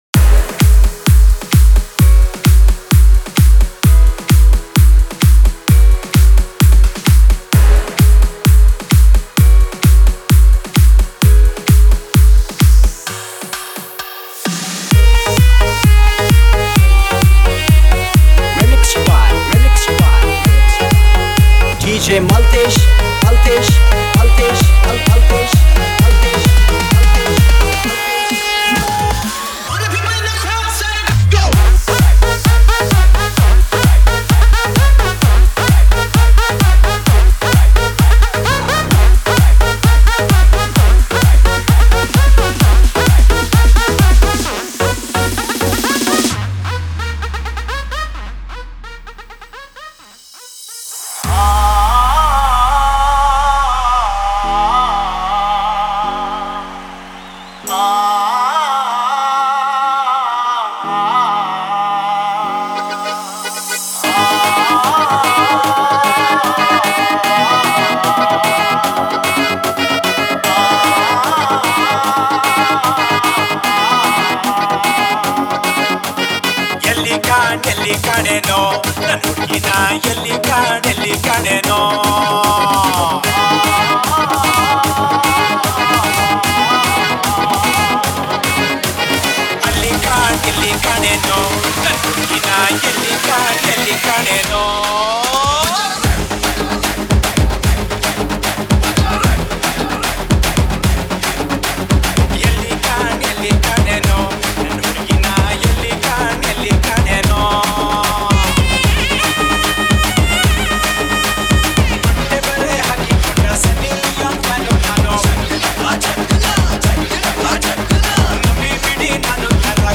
KANNADA DJ SINGLE